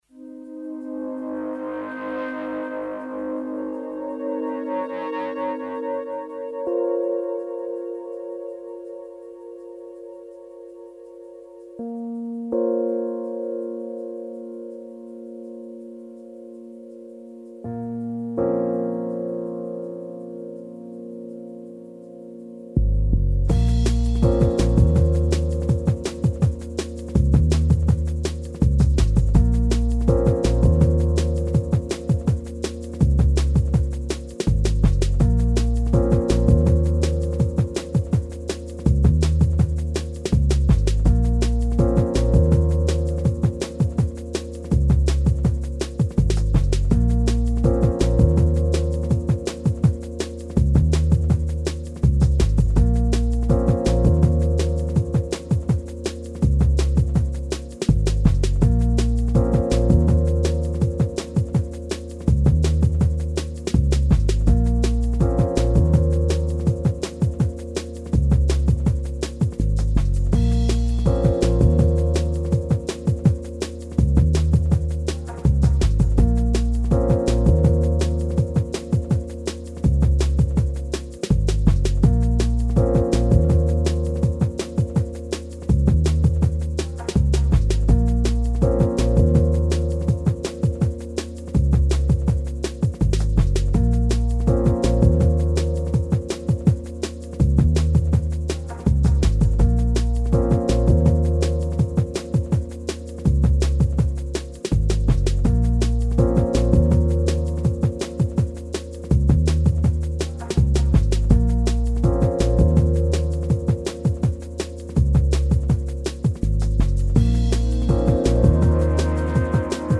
One of my favourite D+B grooves. Goes around and around...